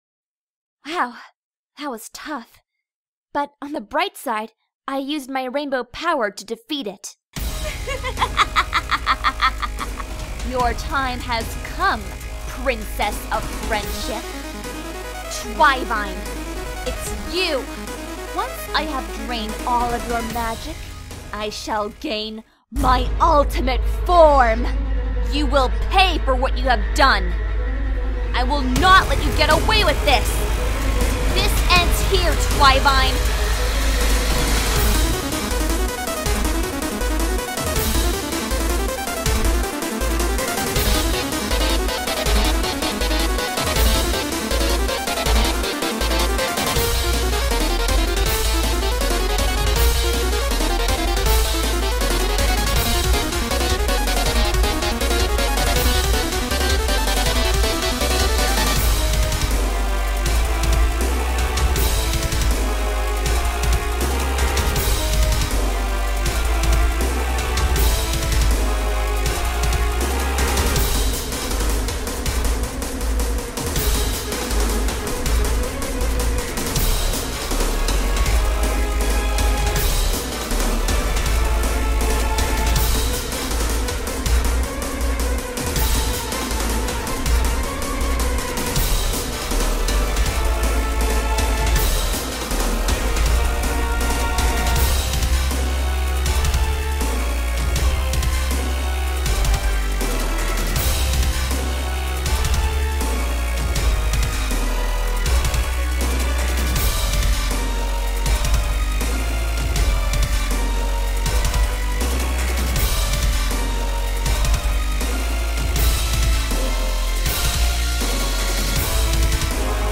genre:soundtrack